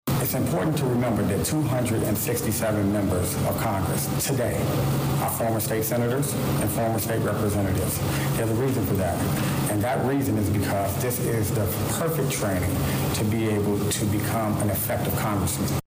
Candidate for Congress, State Senator Willie Preston, speaks with reporters during a Wednesday morning stop in Danville at the Roselawn Fitness Center.